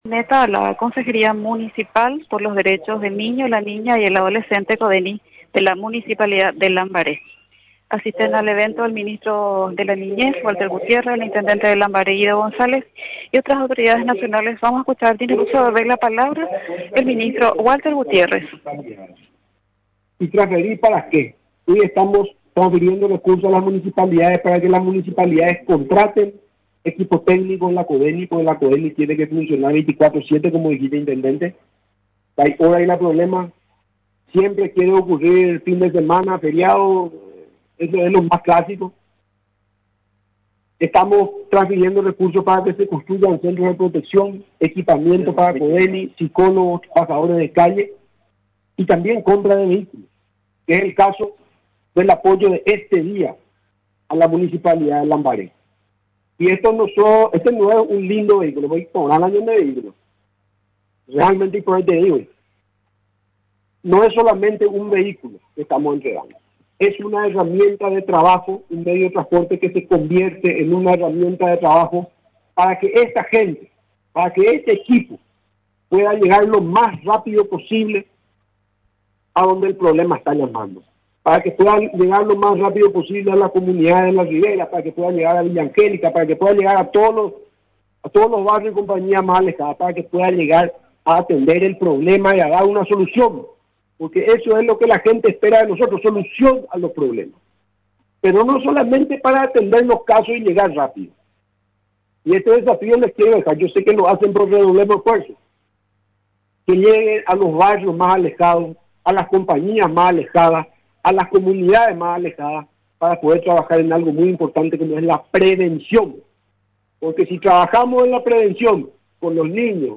El Ministerio de la Niñez y la Adolescencia entregó este martes un móvil a la CODENI de Lambaré, en un acto realizado en la plaza ubicada en el centro de la citada ciudad del departamento Central.